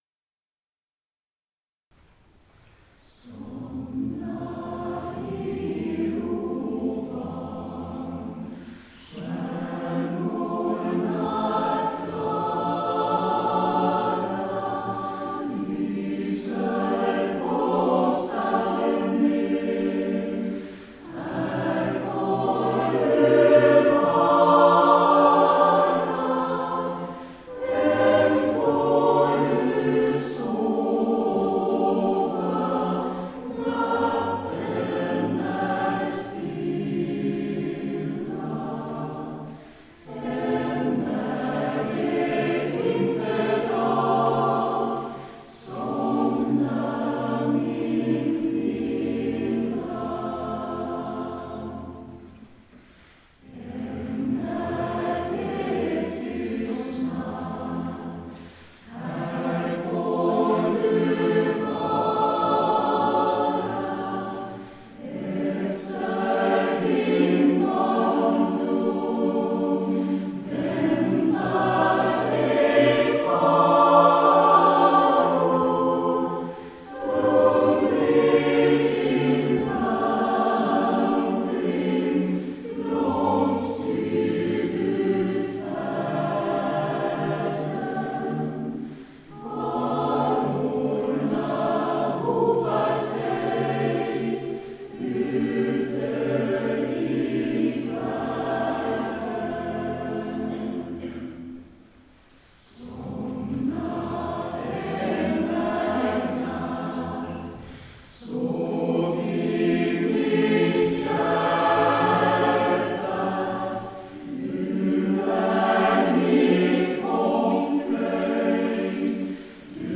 Nyårskonsert 2015 Ängelholms kyrka